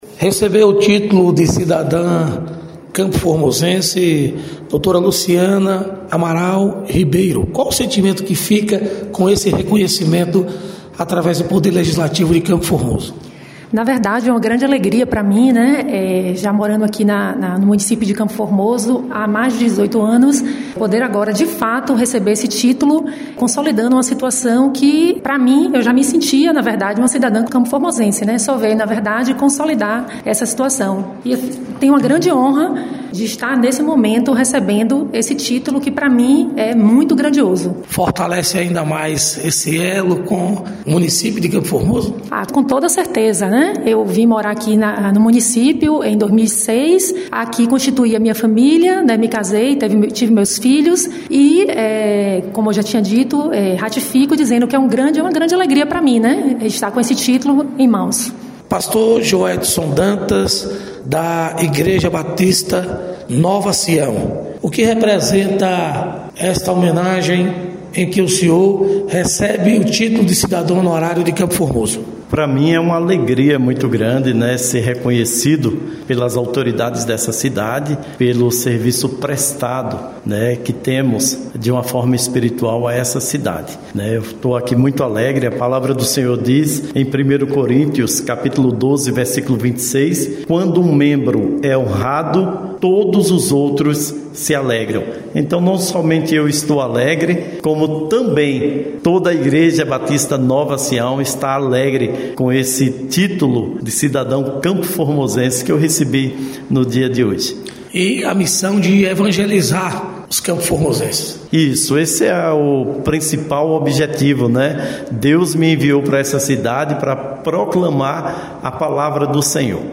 Entrevista com pessoas que receberam o título de cidadãos campoformosense